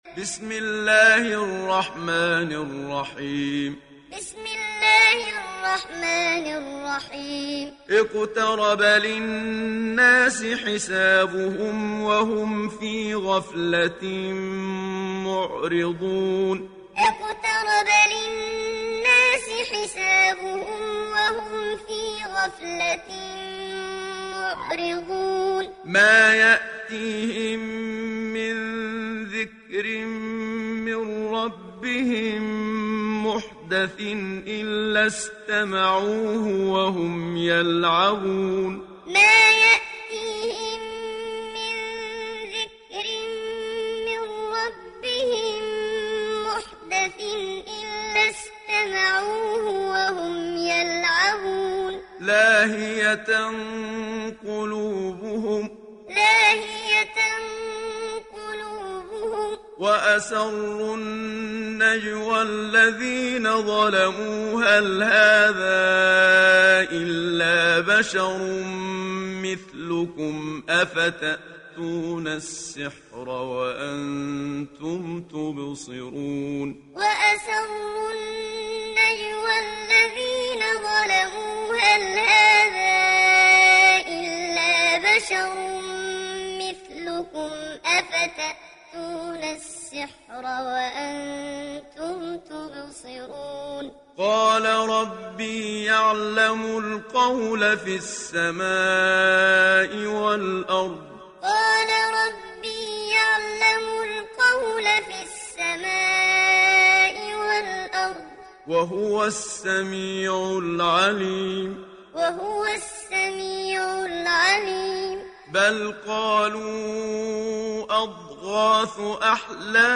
دانلود سوره الأنبياء mp3 محمد صديق المنشاوي معلم روایت حفص از عاصم, قرآن را دانلود کنید و گوش کن mp3 ، لینک مستقیم کامل
دانلود سوره الأنبياء محمد صديق المنشاوي معلم